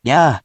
We have our computer friend, QUIZBO™, here to read each of the hiragana aloud to you.
In romaji, 「りゃ」 is transliterated as「rya」which sounds like「lyahh*」.